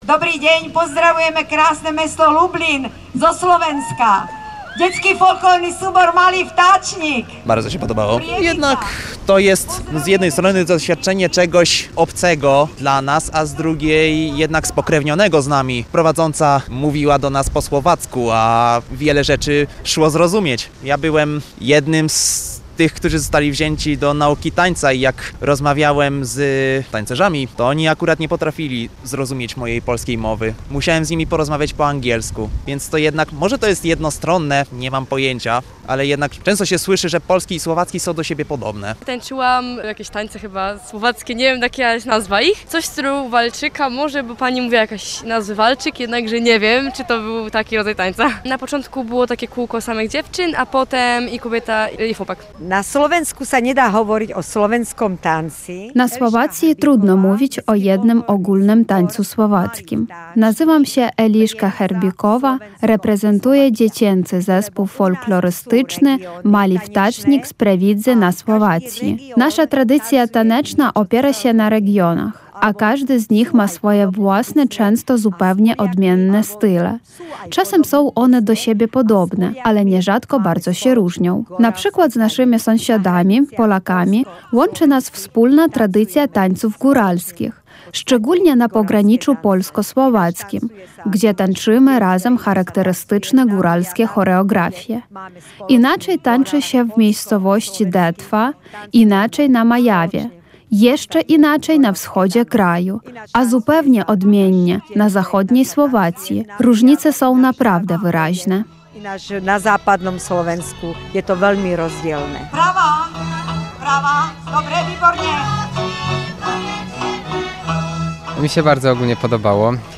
Nasza dziennikarka odwiedziła warsztaty tańca narodowego ze Słowacji — pełne energii i barwnych tradycji, które uczestnicy z entuzjazmem poznawali i praktykowali.